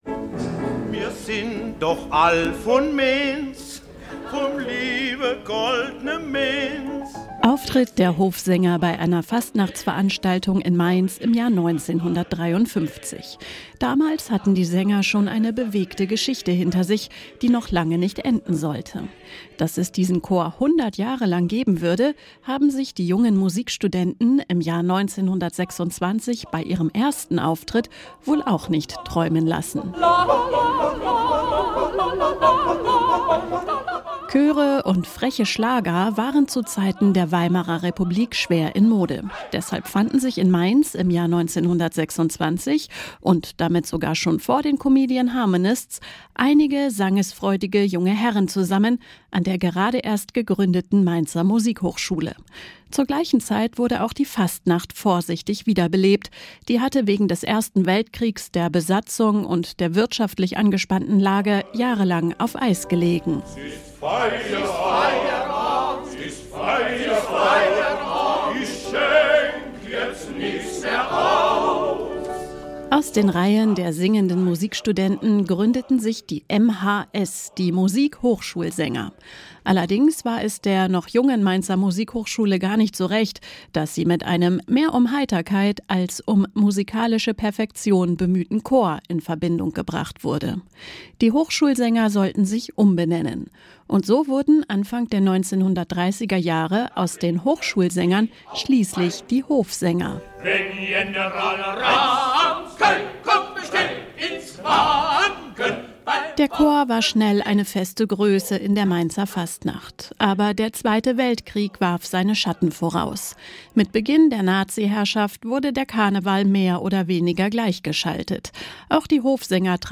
Die Mainzer Hofsänger: Seit 1926 stehen sie für stimmgewaltige Fastnacht, musikalische Qualität und den ganz besonderen Klang der Landeshauptstadt.